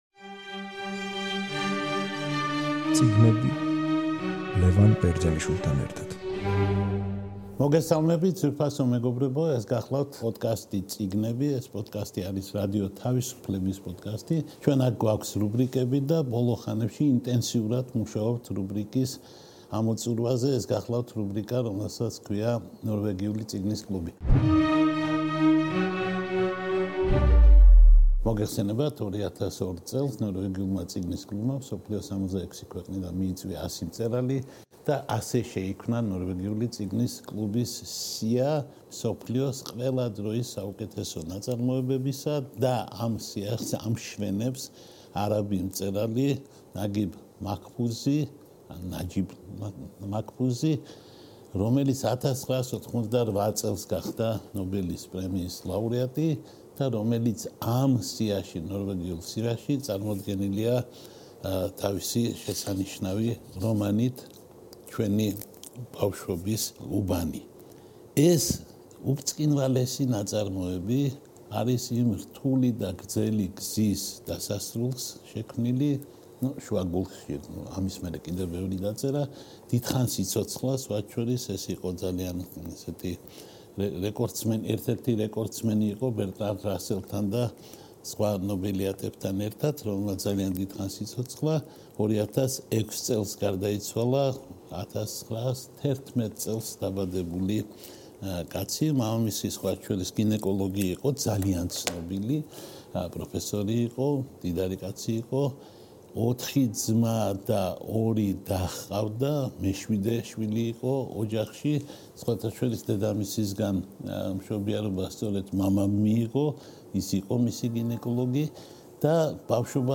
რადიო თავისუფლების პოდკასტი „წიგნები“ და მისი რუბრიკა „ნორვეგიული წიგნის კლუბი“ გთავაზობთ საუბარს დიდ ეგვიპტელ მწერალზე, 1988 წლის ნობელის პრემიის ლაუტეატზე ლიტერატურაში, ნაგიბ მაჰფუზსა და მის რომანზე „ჩვენი უბნის ბავშვები“.